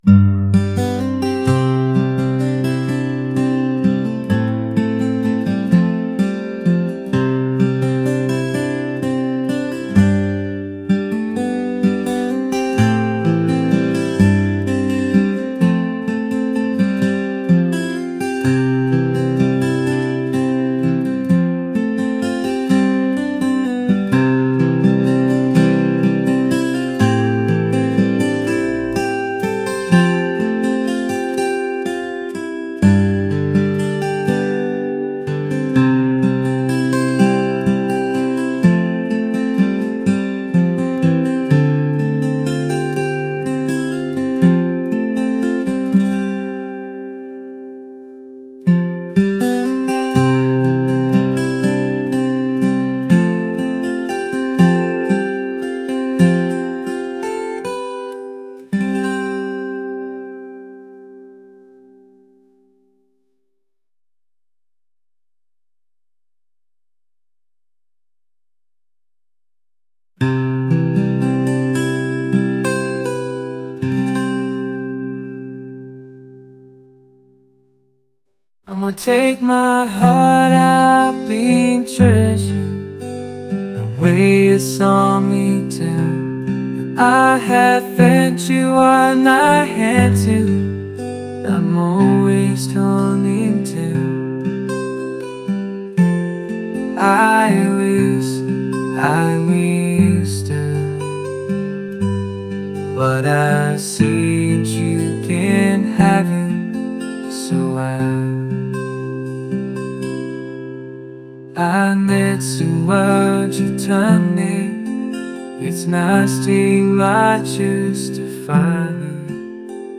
world | folk